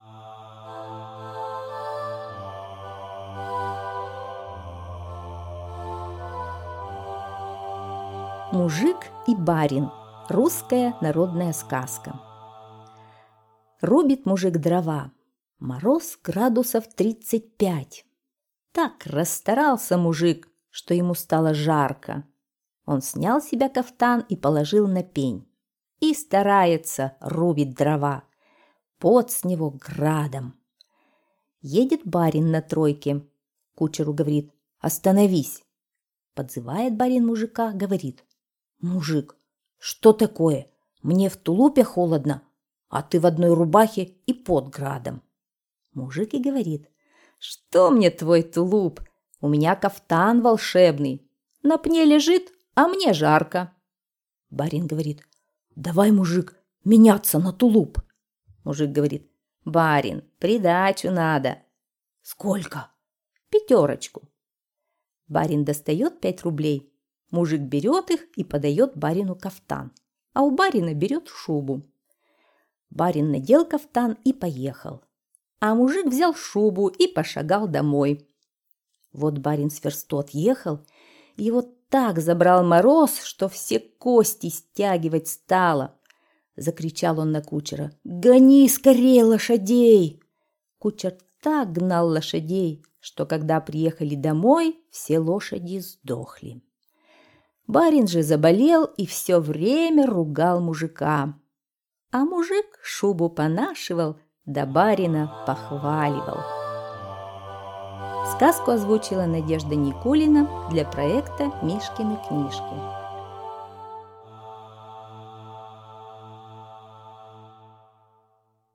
Мужик и барин - русская народная аудиосказка - слушать онлайн